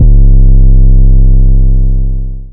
DEEDOTWILL 808 66.wav